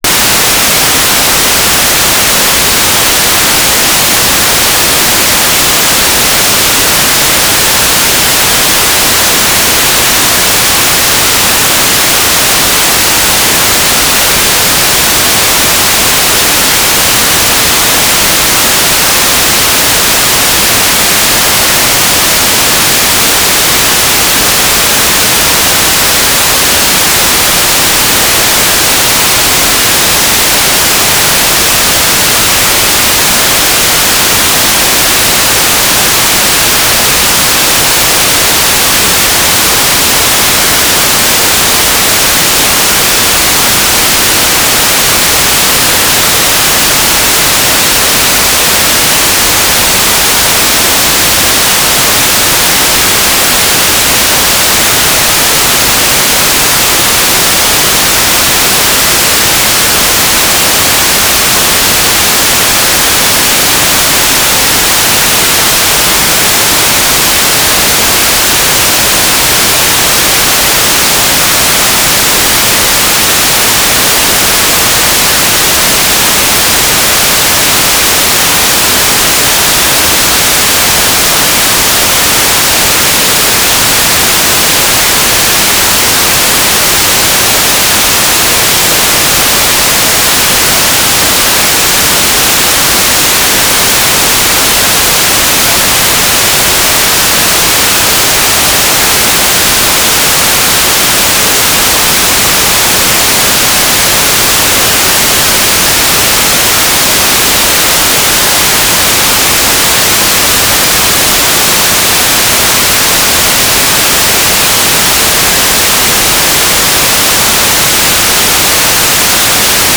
"transmitter_description": "Mode V/U Linear Transponder",
"transmitter_mode": "USB",